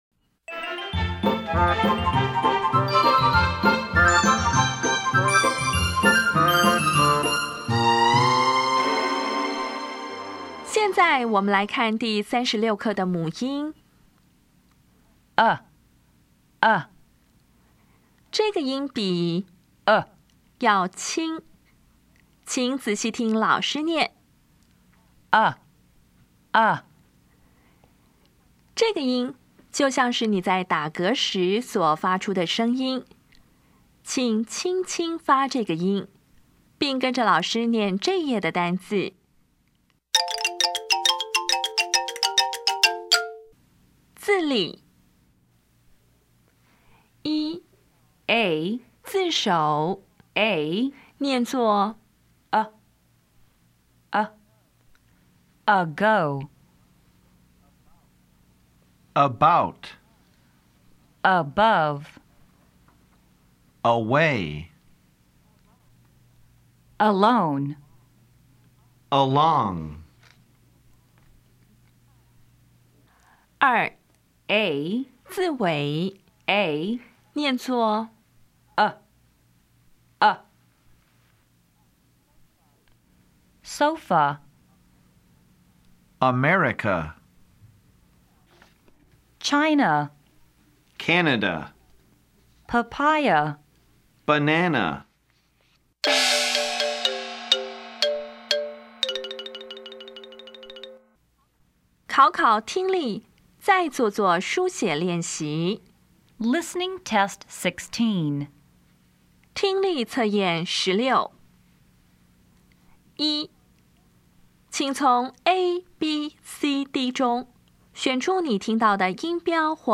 音标讲解第三十六课
[əˋgo]
[əˋbaʊt]
[ˋsofə]
[bəˋnænə]
Listening Test 16